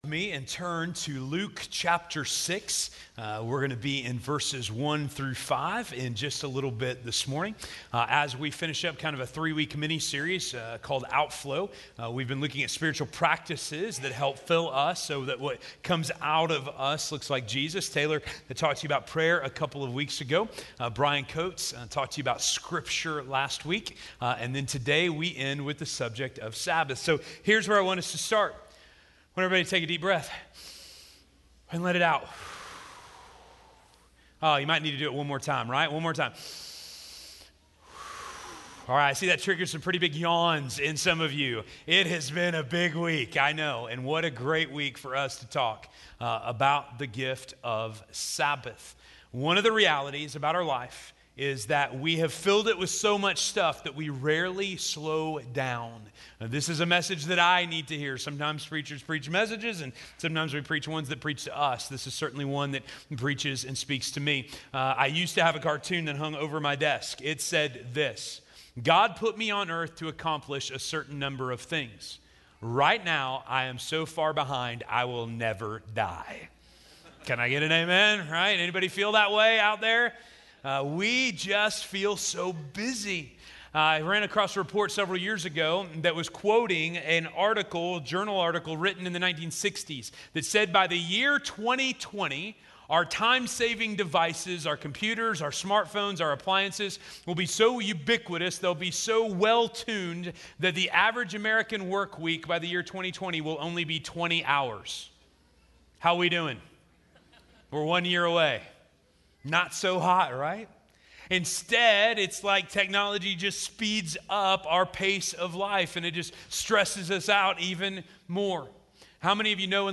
Sabbath - Sermon - Station Hill